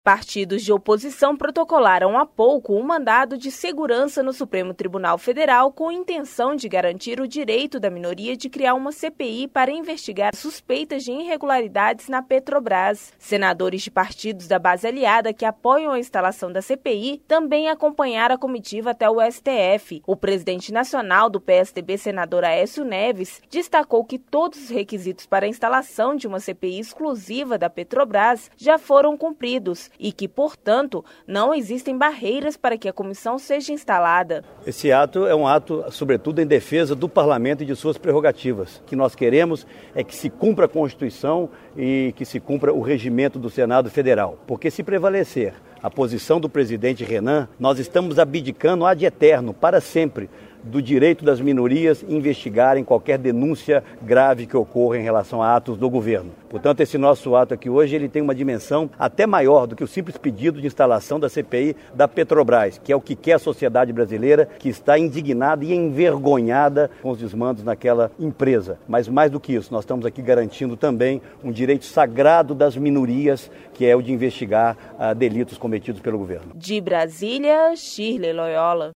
Sonora do senador Aécio Neves
Boletim: